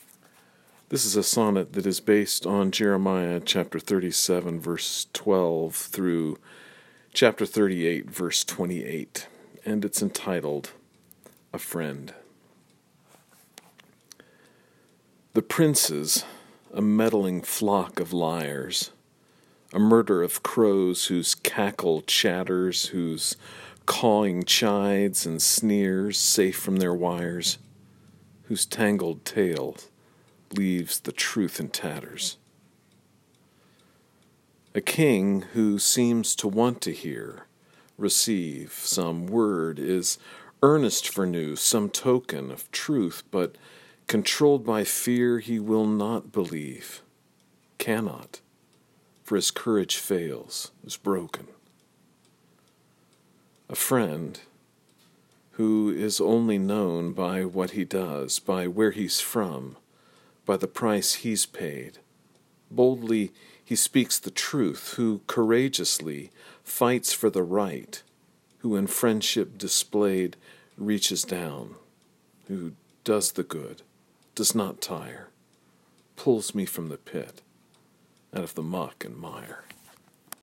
This sonnet is based on Jeremiah 37:12-38:28. If it is helpful you may listen to me read the sonnet via the player below.